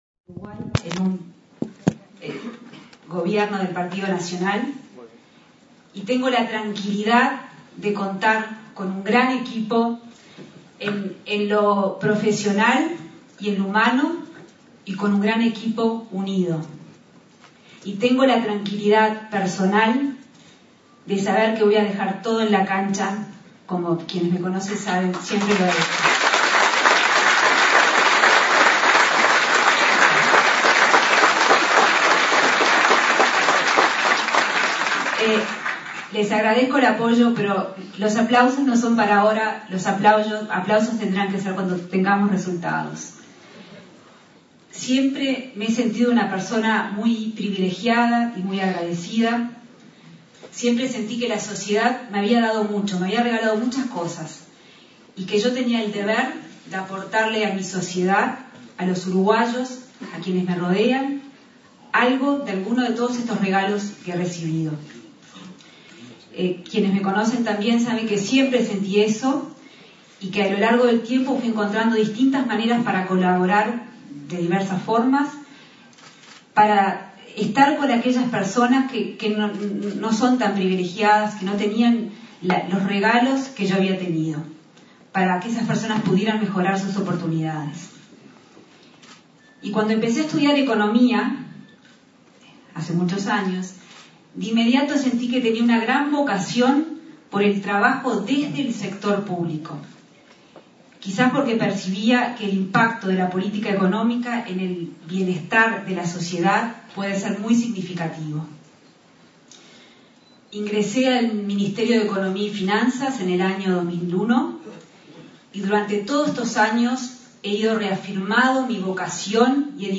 Discurso de Arbeleche en la presentación de ayer como la ministra de Economía para el caso de que el Partido Nacional encabece el próximo gobierno